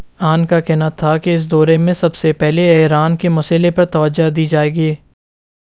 Spoofed_TTS/Speaker_08/266.wav · CSALT/deepfake_detection_dataset_urdu at main